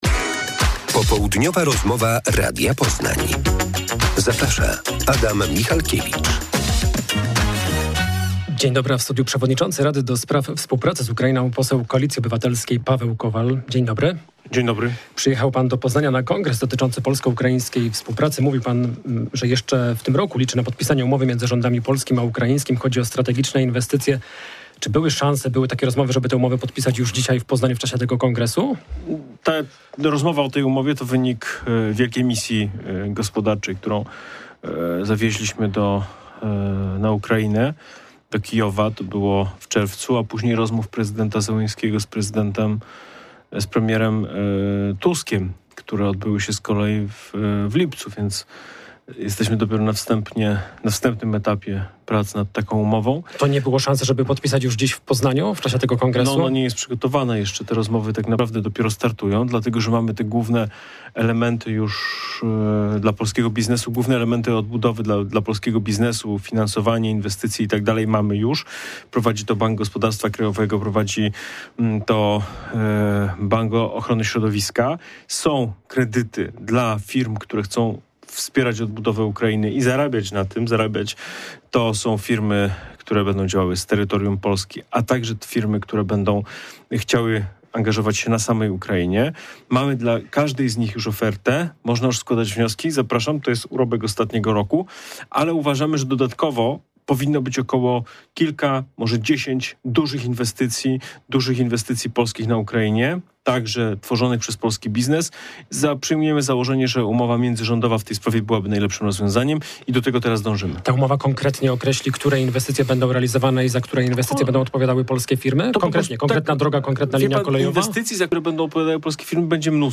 Popołudniowa rozmowa Radia Poznań – Paweł Kowal